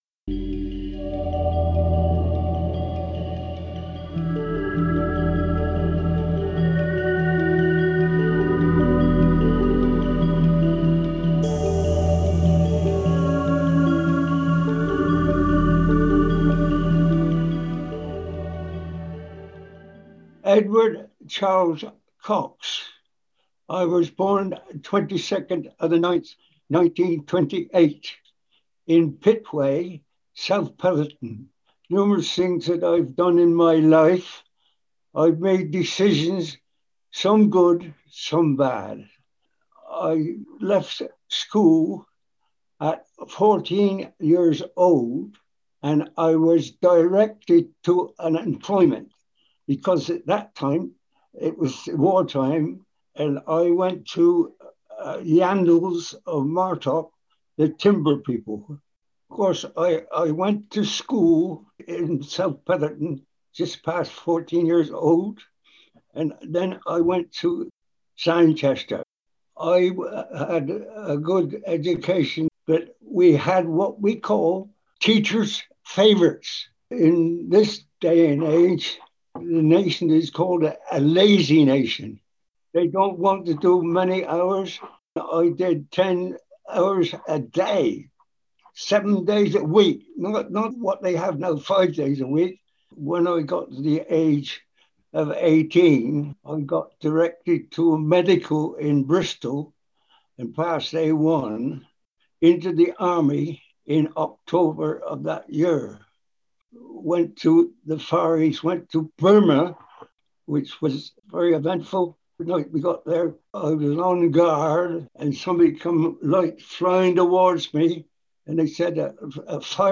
interviewed residents and staff from Somerset Care and local elders from Yeovil